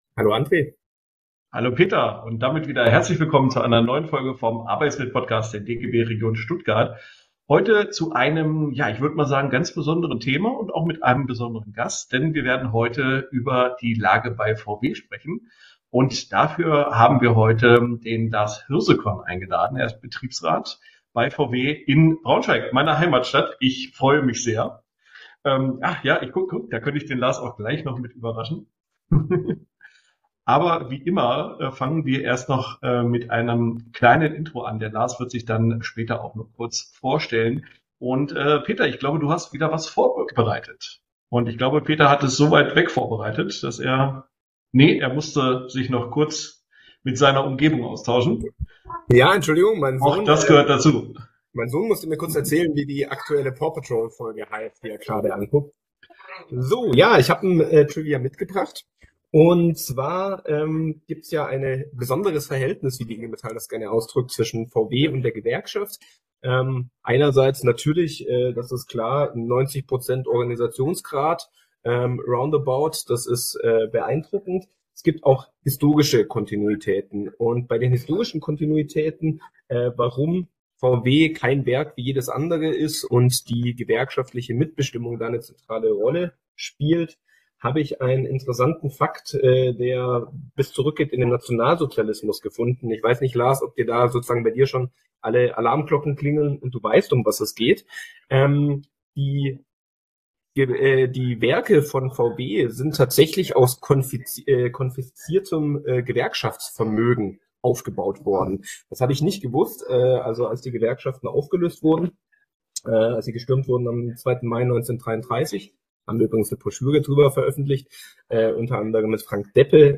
Geschichte von VW 6:24 Die Lage bei VW Hinweis: Der ursprüngliche Upload war fehlerhaft, nun ist aber die korrekte Audiospur zu hören!